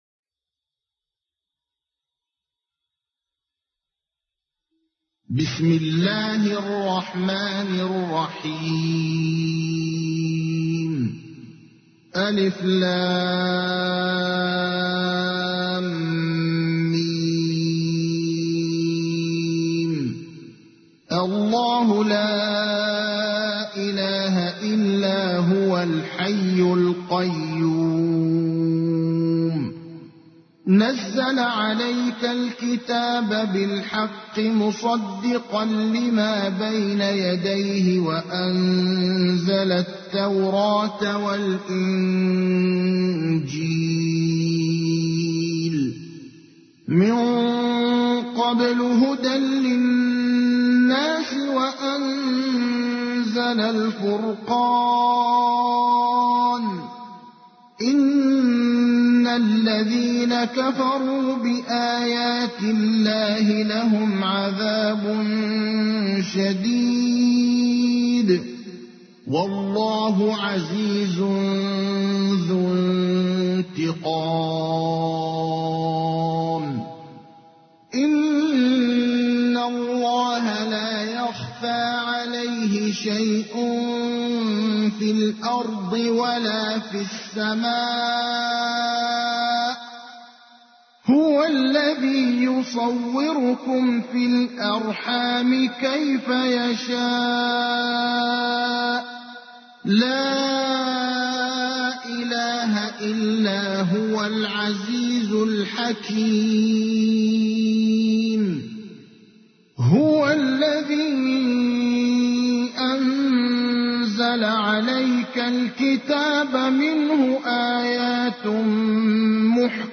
تحميل : 3. سورة آل عمران / القارئ ابراهيم الأخضر / القرآن الكريم / موقع يا حسين